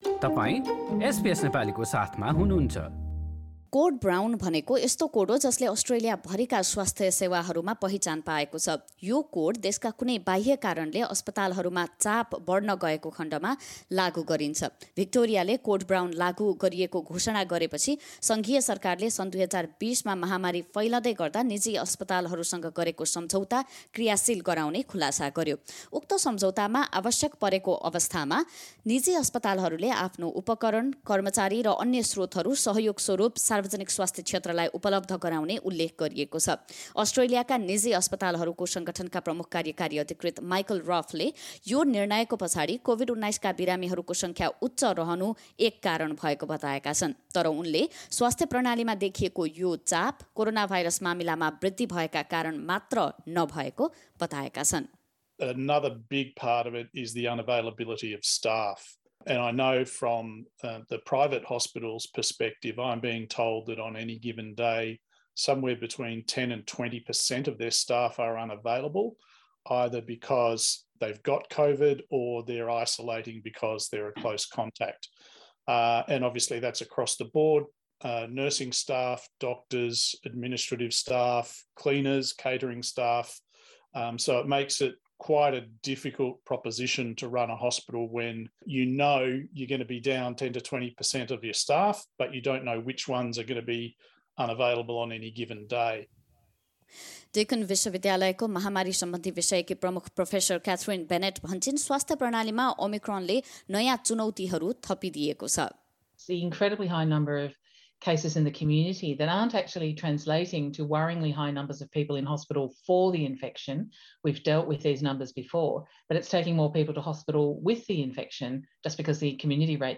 रिपोर्ट सुन्नुहोस्: LISTEN TO 'कोड ब्राउन' के हो र यसले स्वास्थ्य क्षेत्रमा कस्तो असर पुर्‍याउँछ?